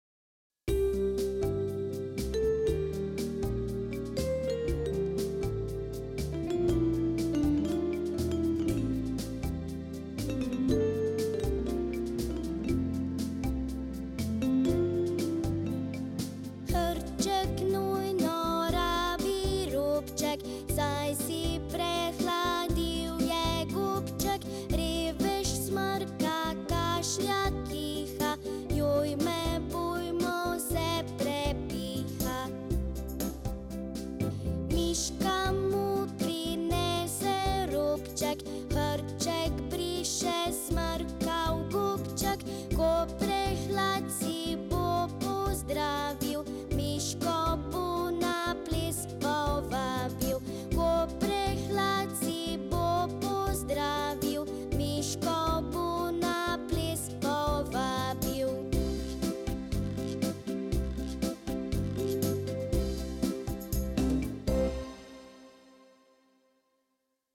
Prehlajeni hrček - Otroška pesmica